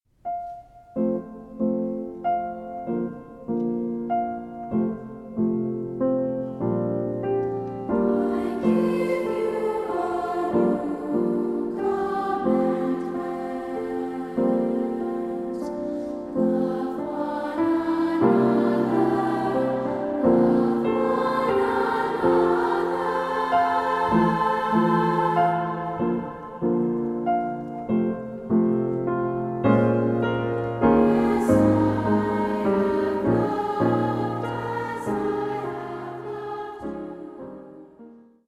Stereo
organ
piano